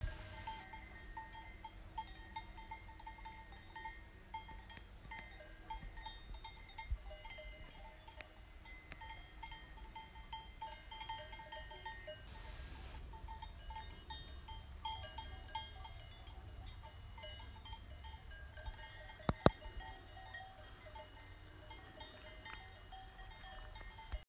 In fact we heard cowbells even it the night.
Recorded at night in Oberstaufen.
You even can hear the crickets 😊
Cow bells.mp2